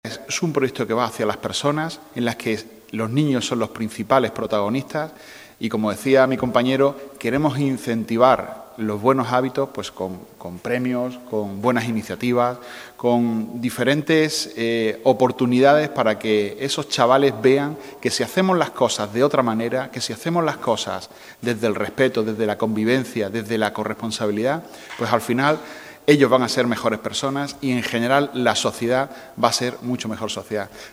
ANTONIO-URDIALES-CONCEJAL-SOSTENIBILIDAD-MEDIOAMBIENTAL.mp3